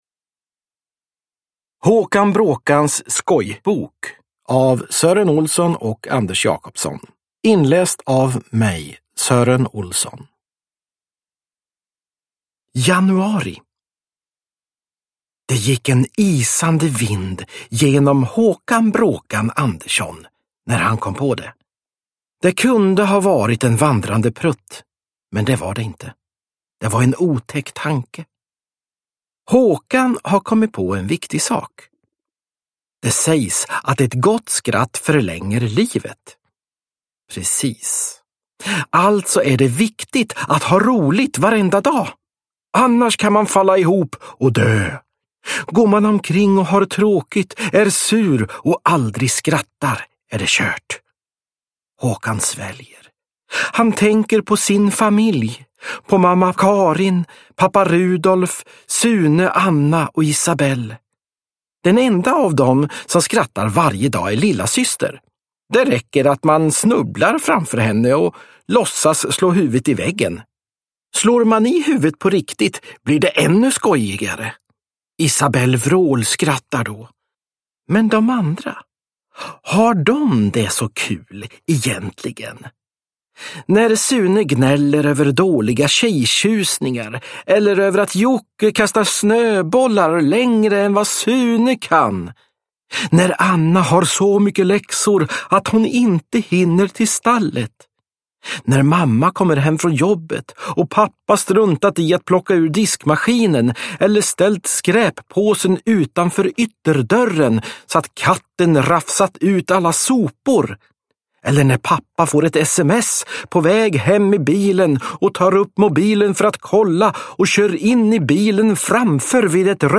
Håkan Bråkans skojbok – Ljudbok – Laddas ner
Uppläsare: Sören Olsson